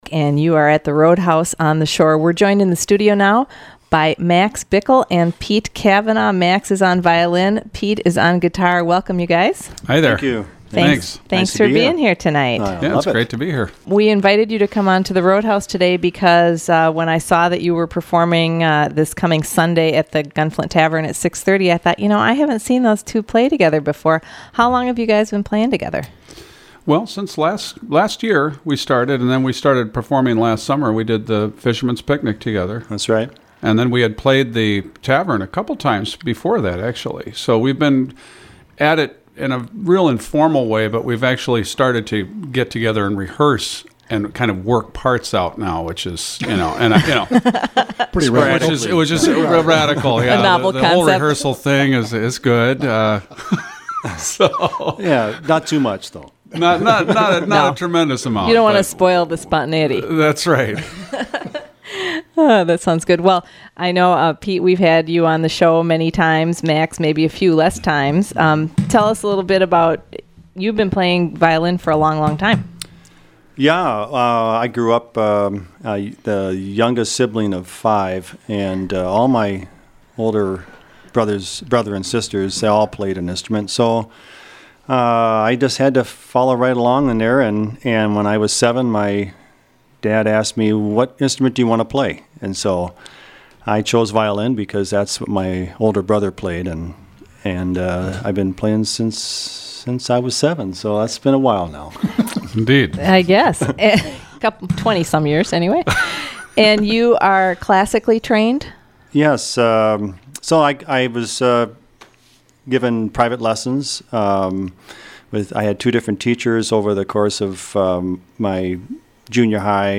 play Studio A
guitar
violin
session of music and chatter